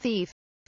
thief kelimesinin anlamı, resimli anlatımı ve sesli okunuşu